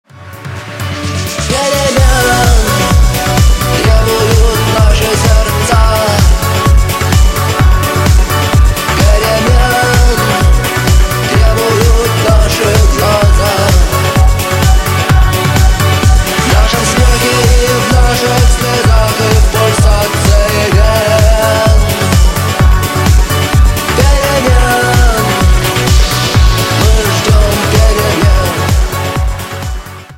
Рок Металл # Ремикс